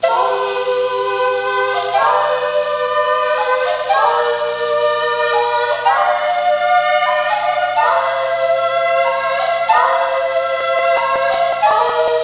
Flute Solo Sound Effect
Download a high-quality flute solo sound effect.
flute-solo.mp3